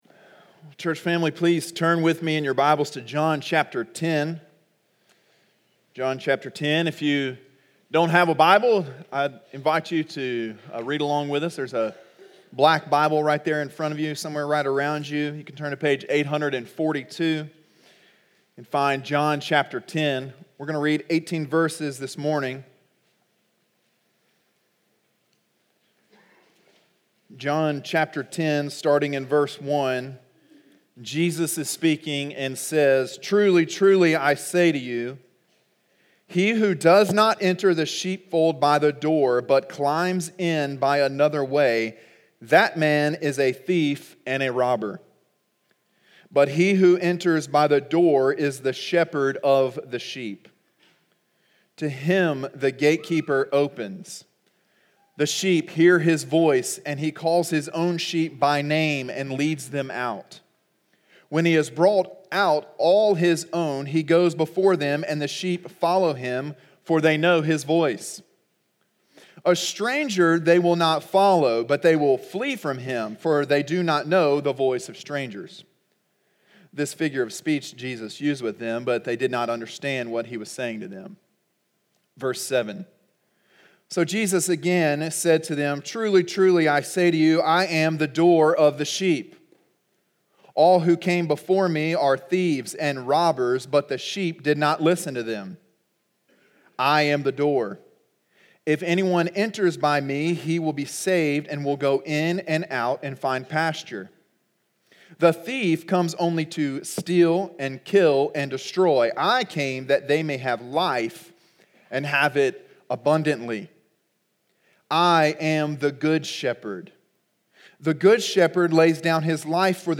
Sermon: “The Good Shepherd” (John 10:1-18) – Calvary Baptist Church